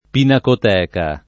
pinacoteca [ pinakot $ ka ]